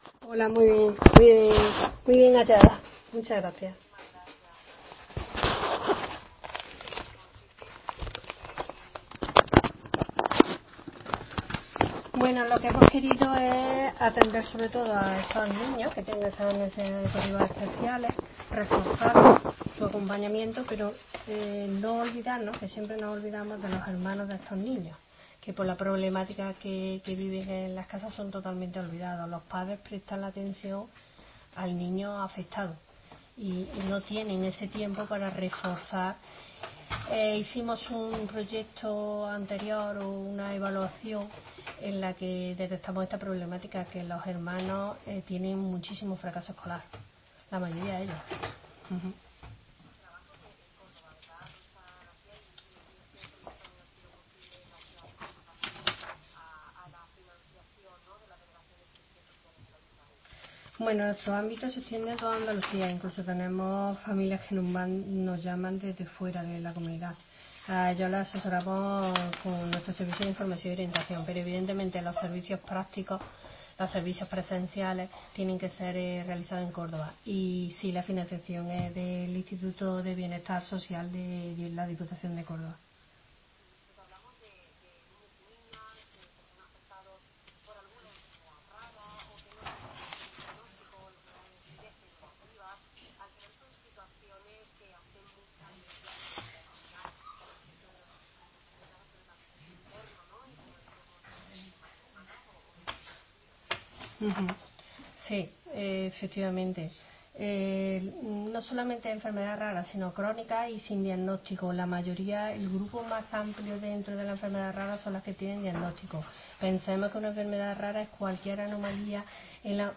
ENTREVISTA RADIO Onda Local Andalucía.